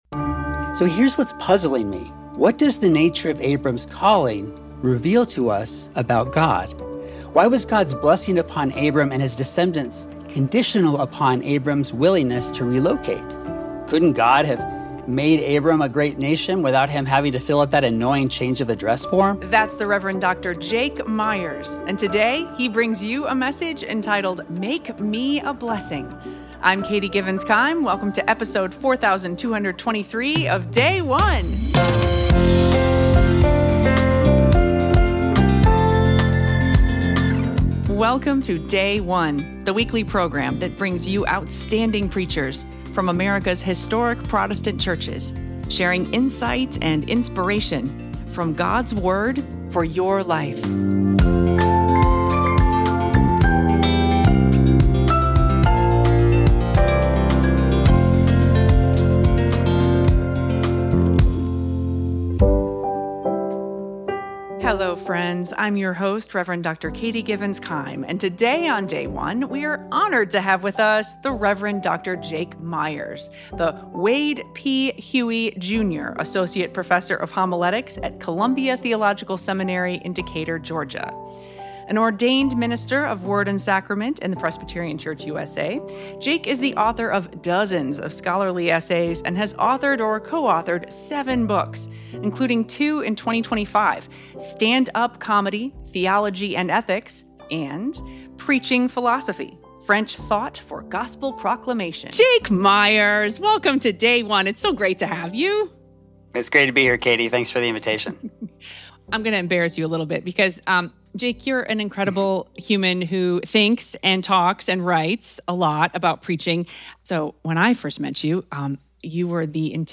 2nd Sunday in Lent - Year A Genesis 12:1–4